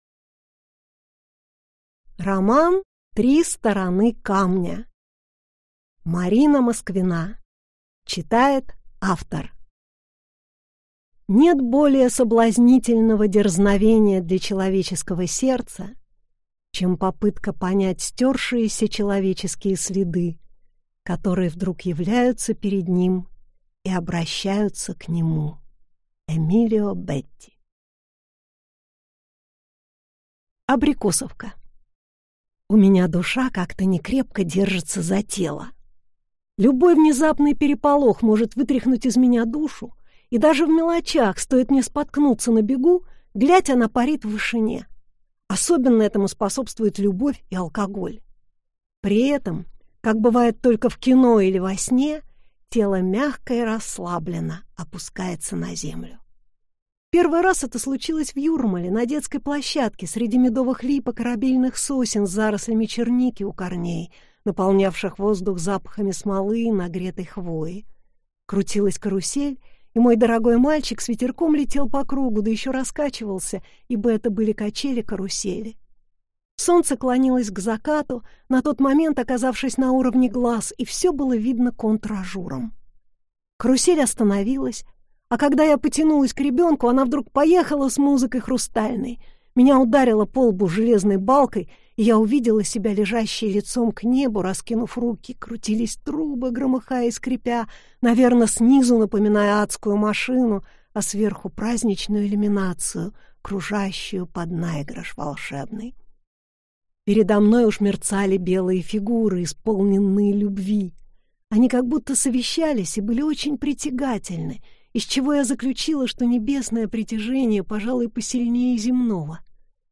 Аудиокнига Три стороны камня | Библиотека аудиокниг